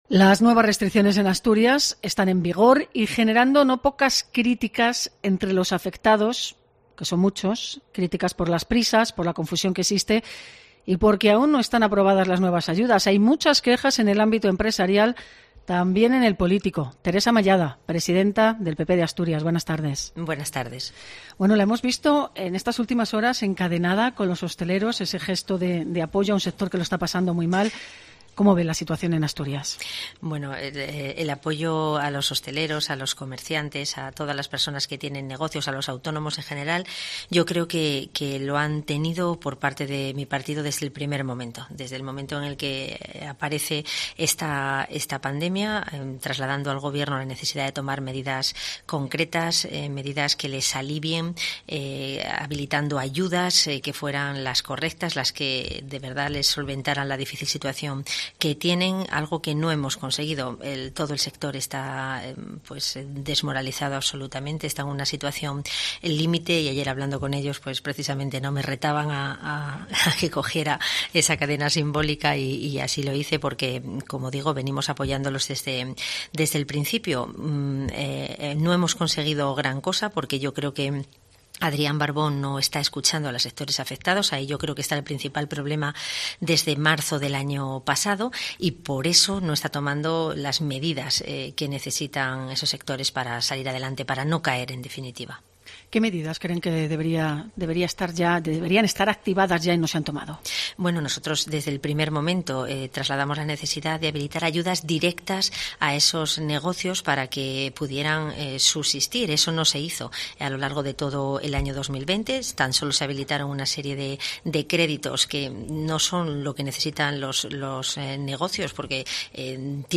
Entrevista a Teresa Mallada, presidenta del PP de Asturias